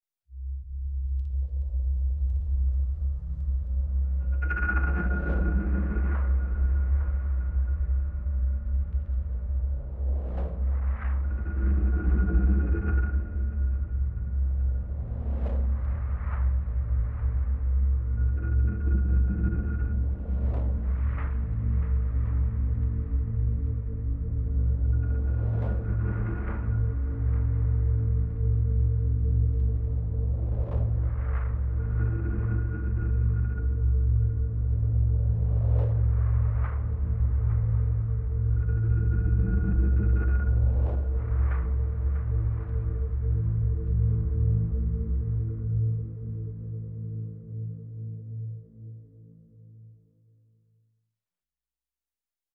Инструментал: